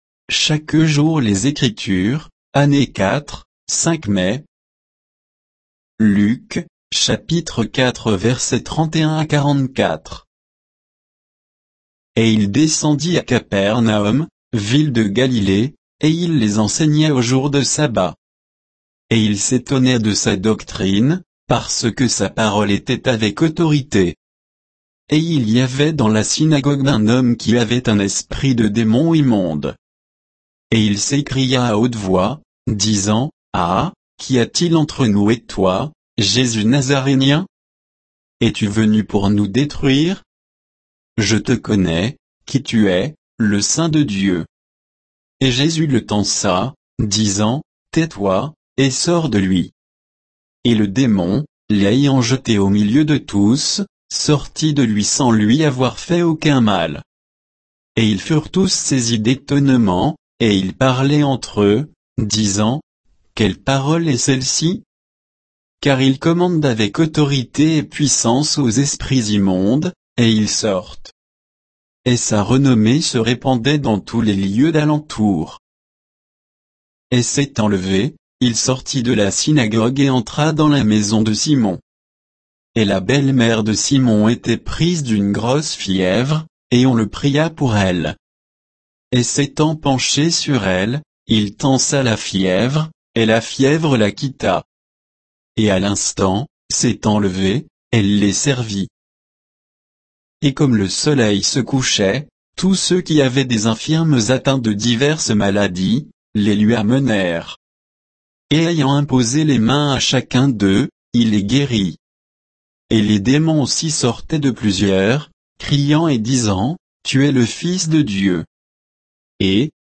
Méditation quoditienne de Chaque jour les Écritures sur Luc 4, 31 à 44